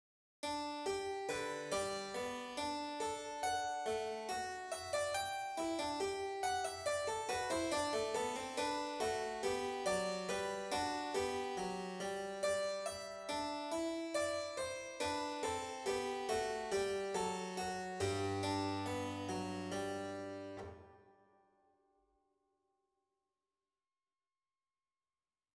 헨델의 G장조 건반 샤콘느(HWV 442)의 마지막 변주는 오른손이 왼손을 한 박자 간격으로 모방하는 카논이다.